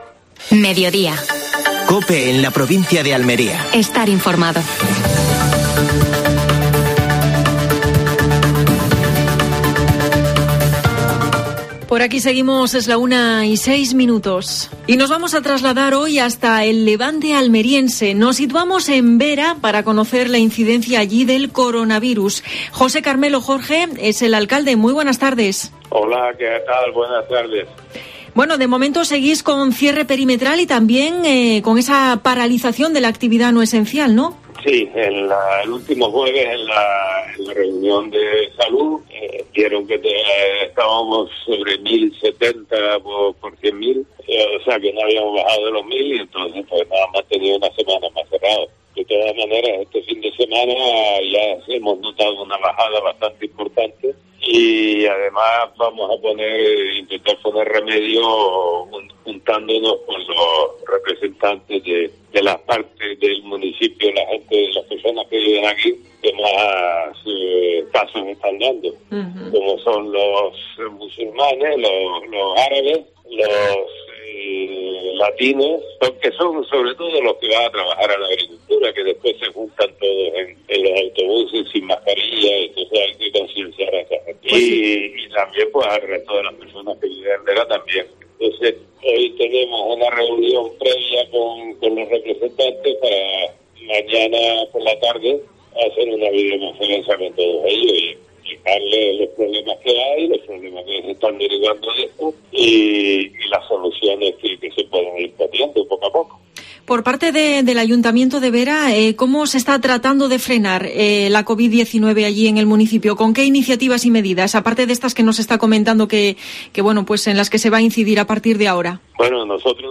AUDIO: Actualidad en Almería. Entrevista al alcalde de Vera.